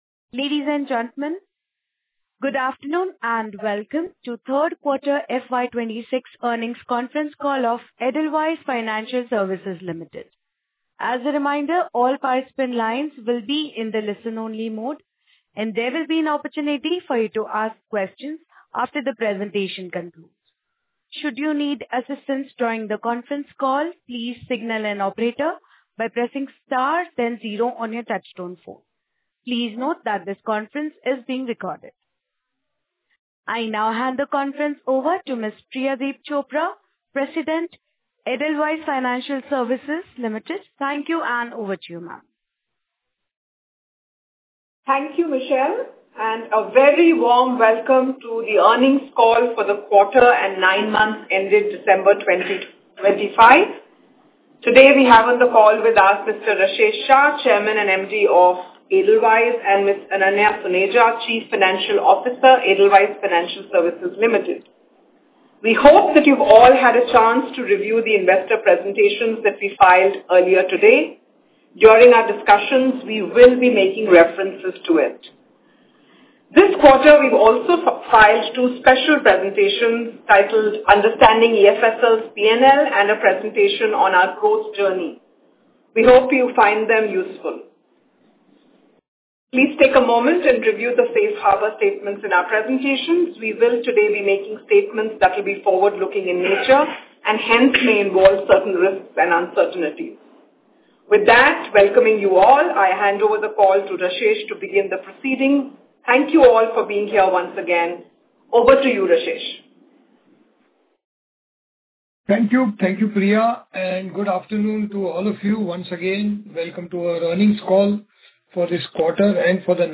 Earnings-Call-Audio-Q3FY26.mp3